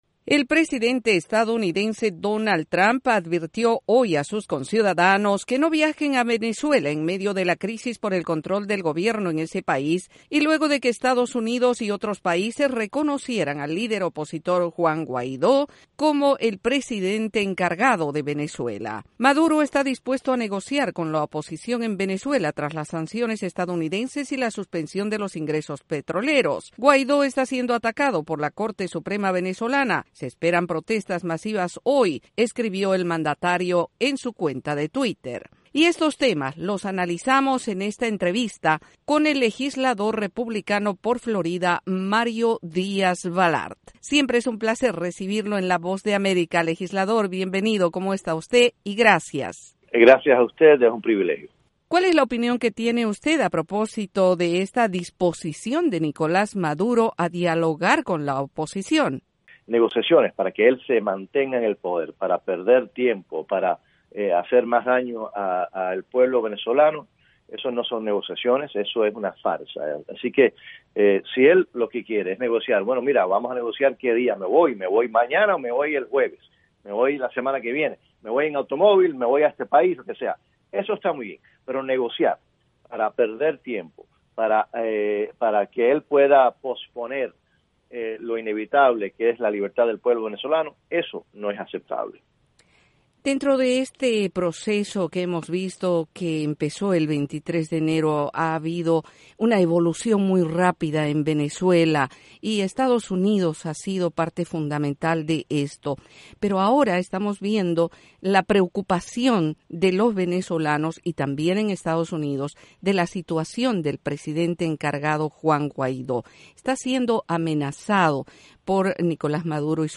Y sobre este tema la Voz de América entrevistó al legislador republicano por Florida, Mario Díaz Balart, quien aseguró que "la única negociación posible con Nicolás Maduro es cuando y como dejará el gobierno".